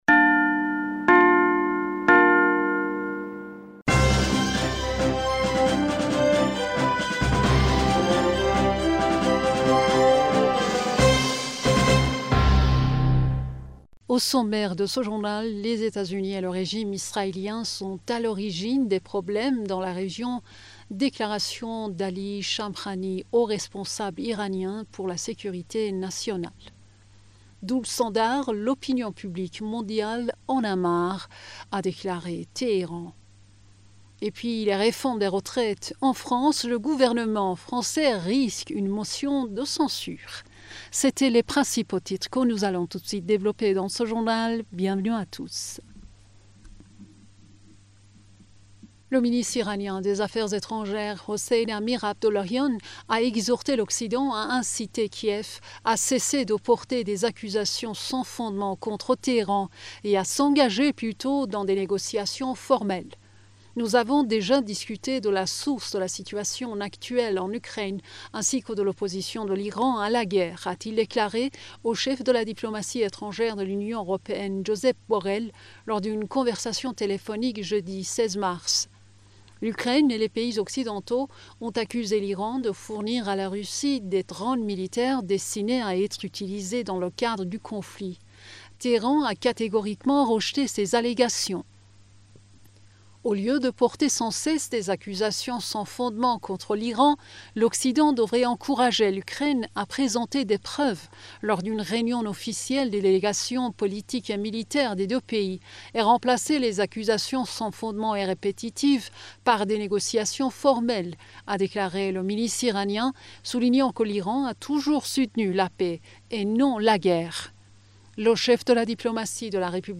Bulletin d'information du 17 Mars